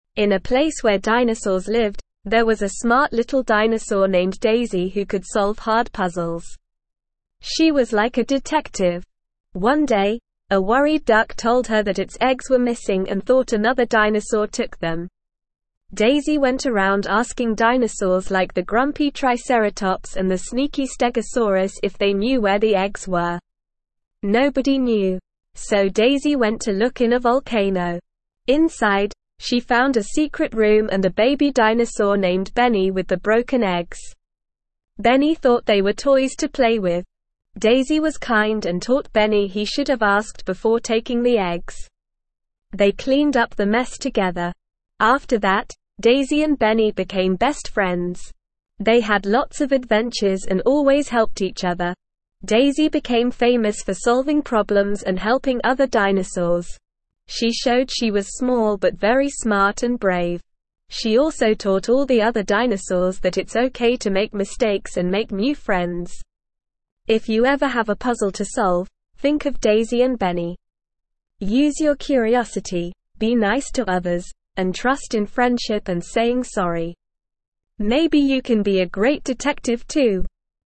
Normal
ESL-Short-Stories-for-Kids-Lower-Intermediate-NORMAL-Reading-The-Dinosaur-Detective.mp3